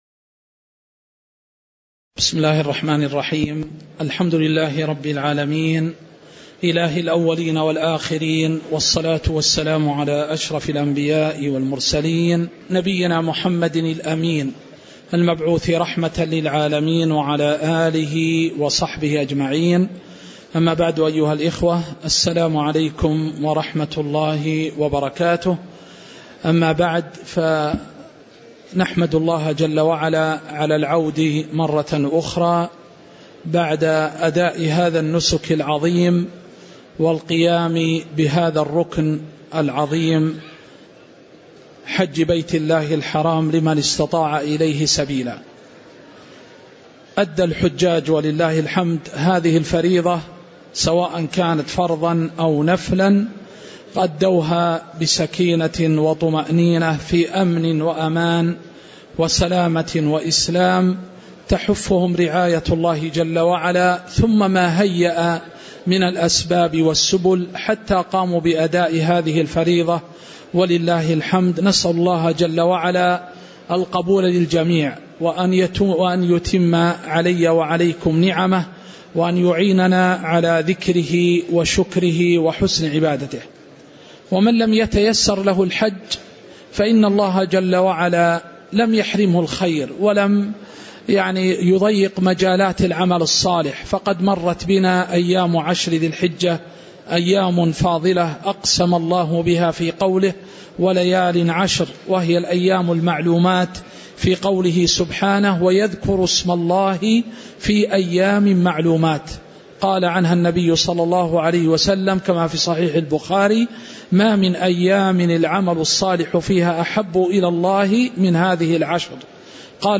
تاريخ النشر ١٦ ذو الحجة ١٤٤٠ هـ المكان: المسجد النبوي الشيخ